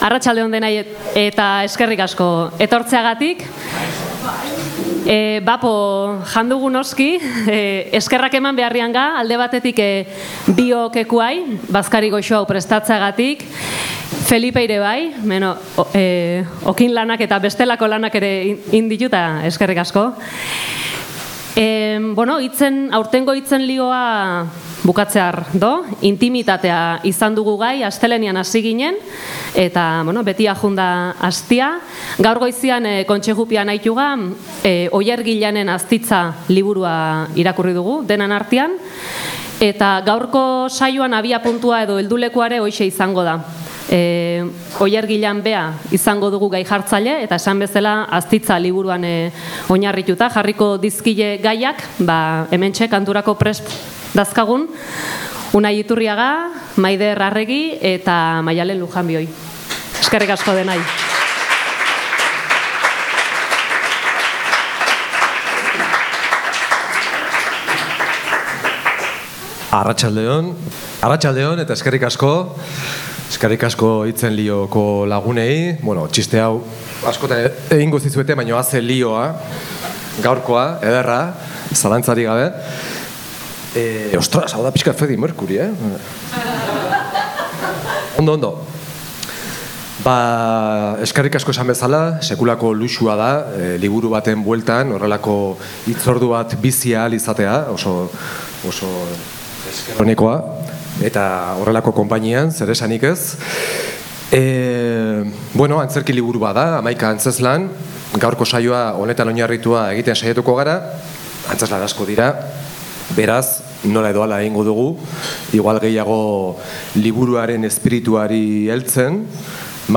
“Aztitza”-ren bertso bazkari literarioa – Oiartzun Irratia
bertso bazkari literarioa antolatu zuten